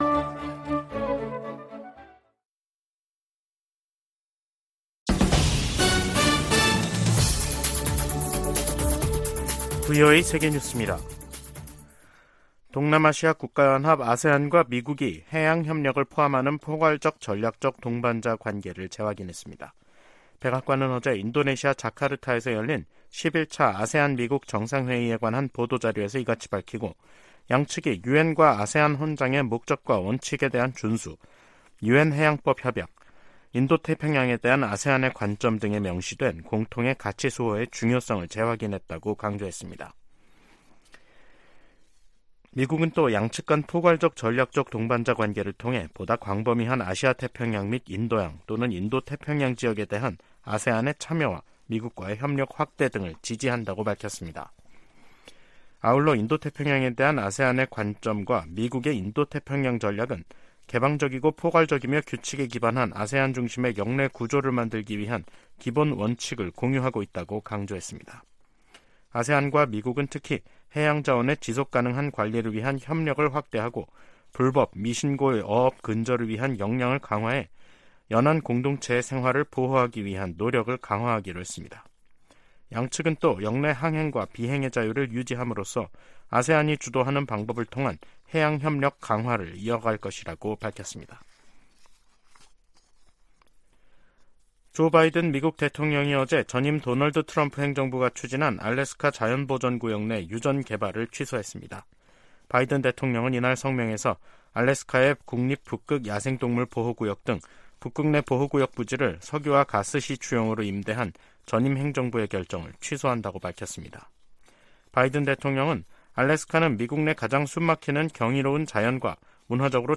VOA 한국어 간판 뉴스 프로그램 '뉴스 투데이', 2023년 9월 7일 2부 방송입니다. 존 커비 백악관 국가안보회의(NSC) 전략소통조정관은 북한과 러시아 간 무기거래 협상을 면밀히 주시할 것이라고 경고했습니다. 동아시아정상회의(EAS)에 참석한 윤석열 한국 대통령은 대북 제재 준수에 유엔 안보리 상임이사국의 책임이 더 무겁다고 말했습니다. 유럽연합(EU)은 북러 정상회담 가능성에 관해 러시아의 절박한 처지를 보여줄 뿐이라고 지적했습니다.